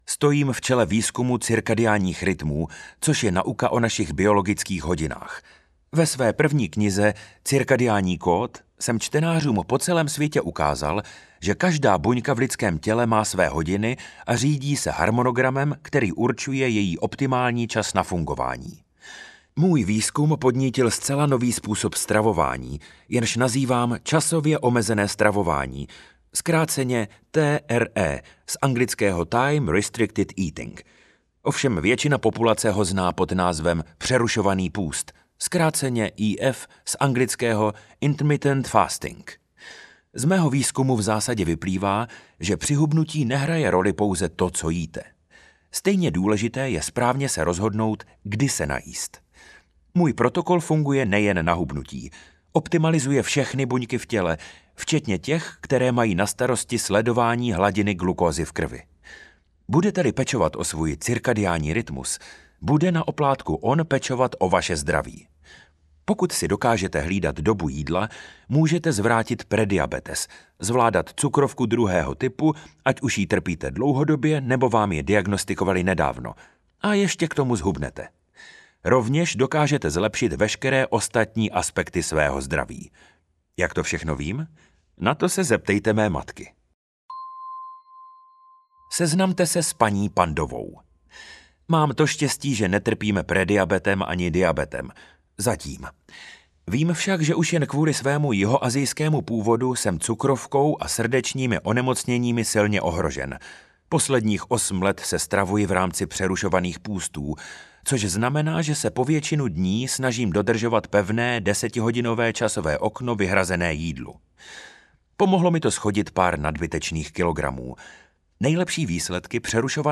Audiokniha Cirkadiánní kód proti cukrovce - Satchin Panda